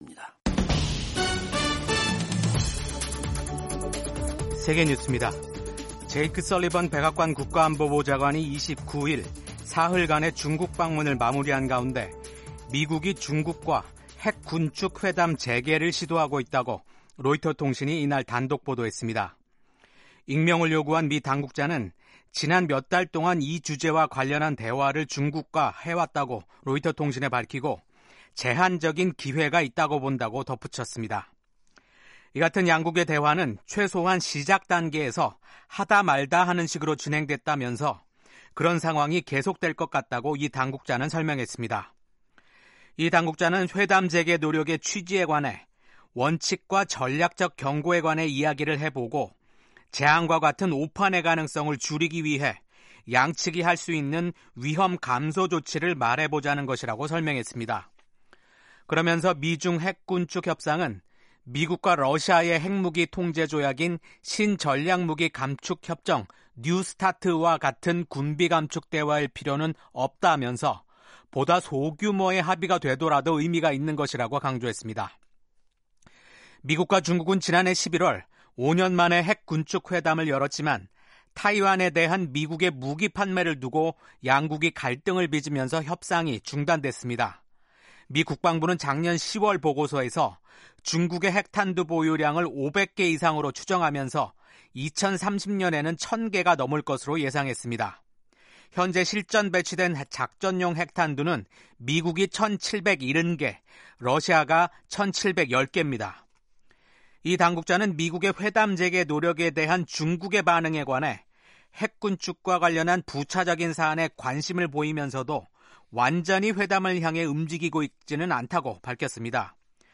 세계 뉴스와 함께 미국의 모든 것을 소개하는 '생방송 여기는 워싱턴입니다', 2024년 8월 30일 아침 방송입니다. 제이크 설리번 미국 백악관 국가안보보좌관이 시진핑 중국 국가주석을 만나 미중 관계의 관리 필요성을 강조했습니다. 미국 민주당 대통령 후보인 카멀라 해리스 부통령과 러닝메이트 팀 월즈 후보가 경합 지역인 조지아주를 방문했습니다.